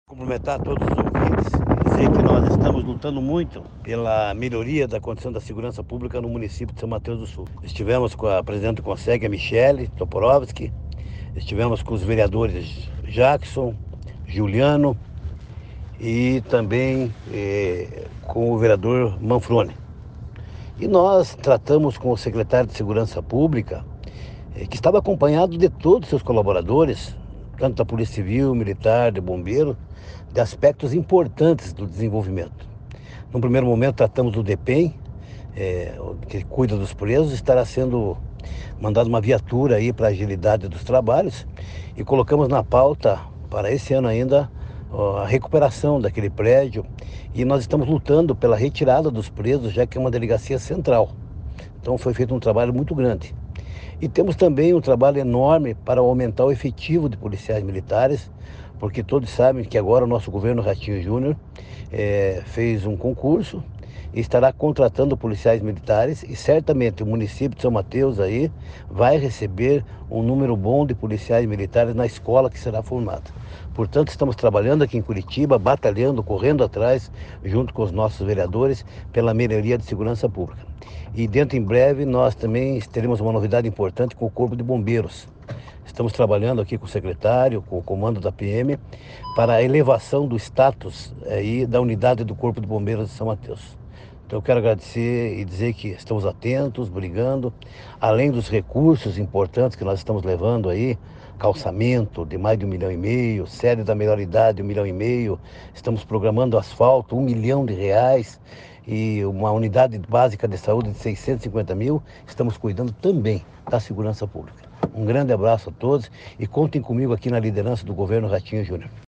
Acompanhe o áudio do Deputado Hussein Bakri: